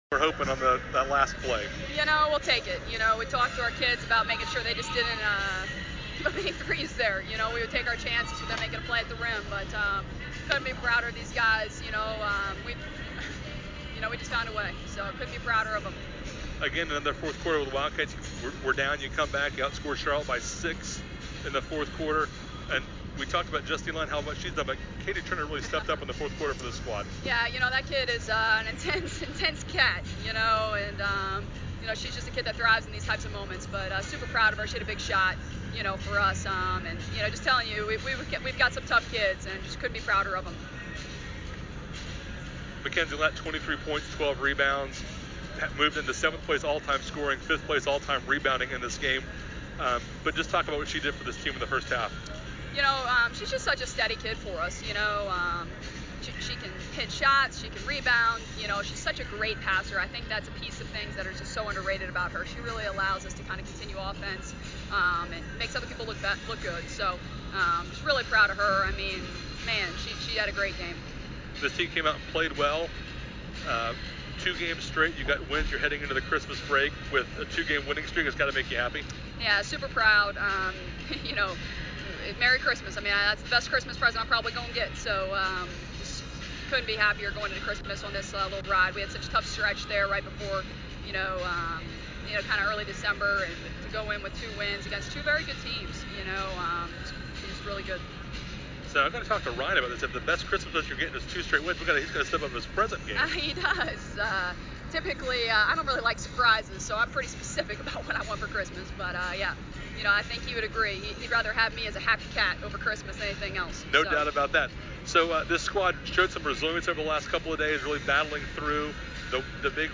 Postgame Comments
Post Game Charlotte WBB.MP3